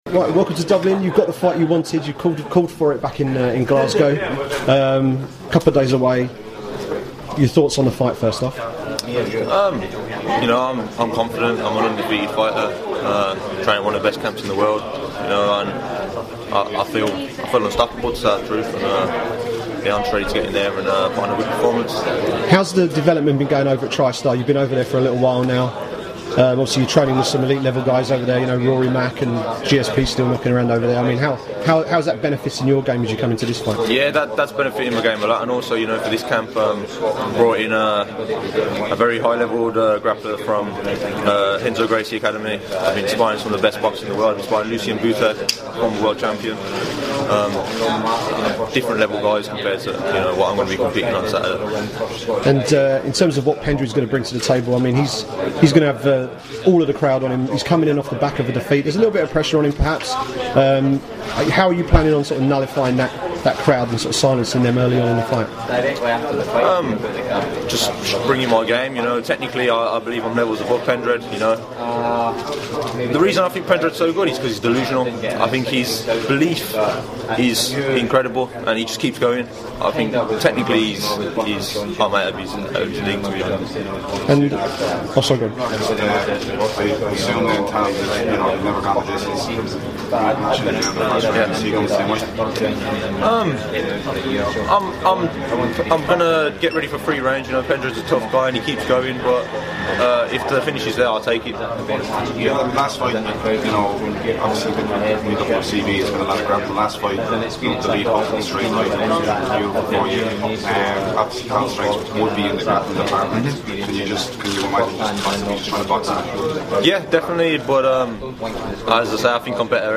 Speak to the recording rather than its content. at the UFC Dublin media day